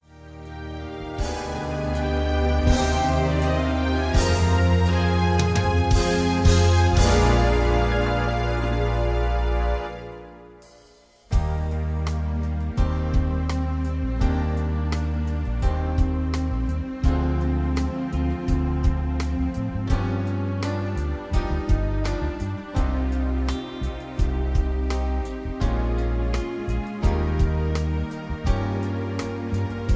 backing tracks
easy listening